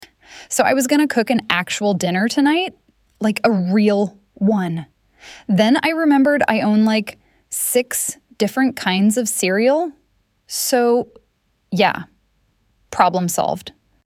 AI Voice Over service
AI voice over for videos, ads, and all types of content with clear and natural sound.
Clean, noise-free audio (MP3 or WAV)
Proper pacing, tone, and pronunciation
Male
CorporateNeutralConversationalAuthoritativeConfident